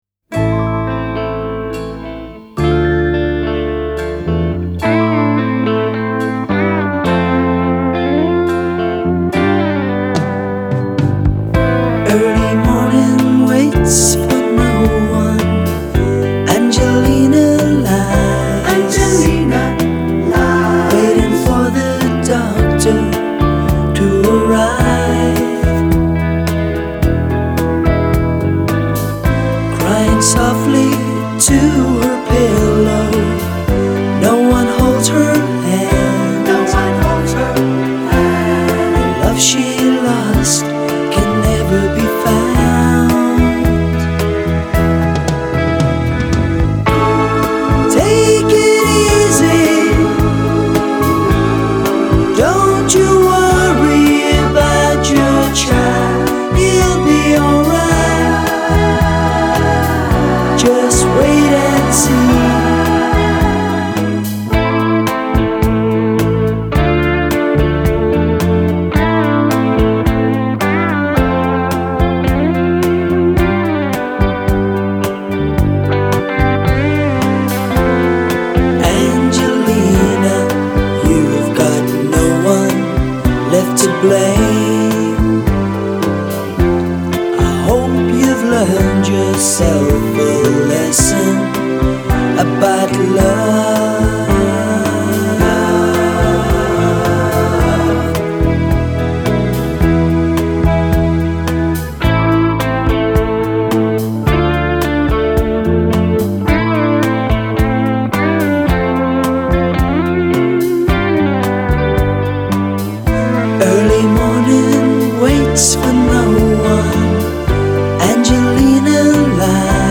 В основном это всё радио-версии.